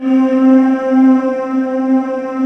Index of /90_sSampleCDs/USB Soundscan vol.28 - Choir Acoustic & Synth [AKAI] 1CD/Partition D/18-HOLD VOXS